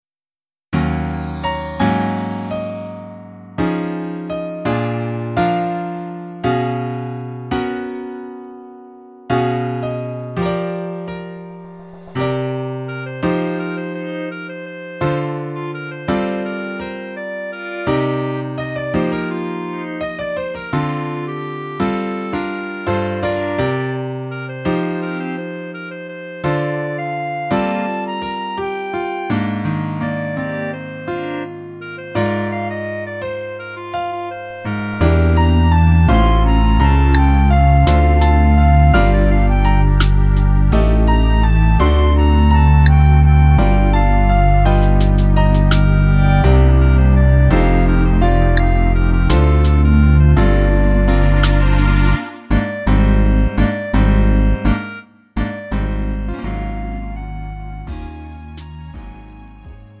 음정 원키 3:57
장르 가요 구분 Lite MR
Lite MR은 저렴한 가격에 간단한 연습이나 취미용으로 활용할 수 있는 가벼운 반주입니다.